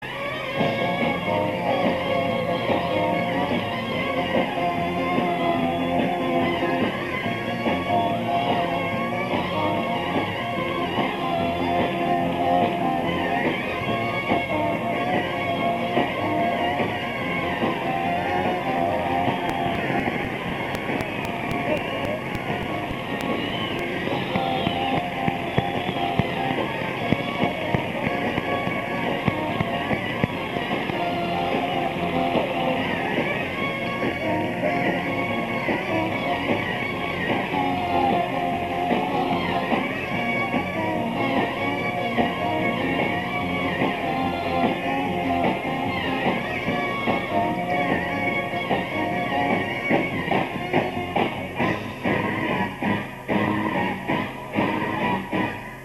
Bootlegs (enregistrements en salle)
Béziers (2 décembre 1977)